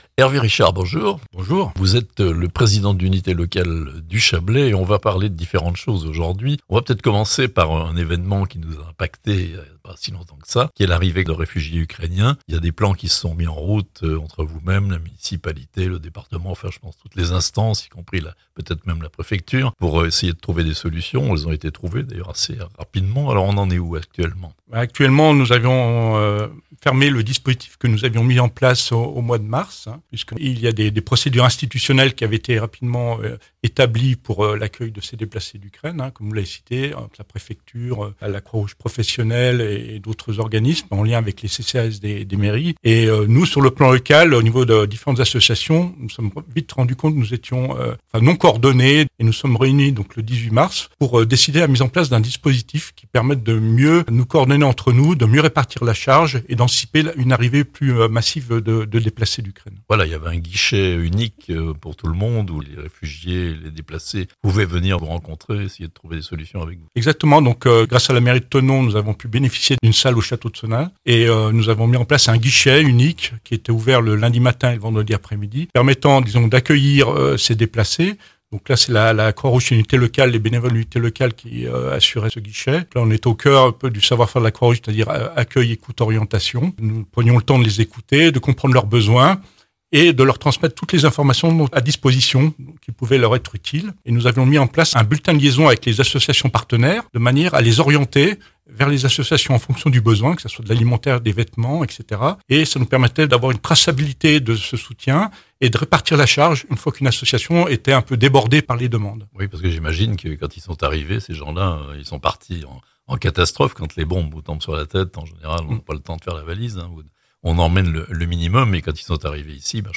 Mission accomplie pour le collectif chablaisien qui a accueilli les déplacés ukrainiens (interview)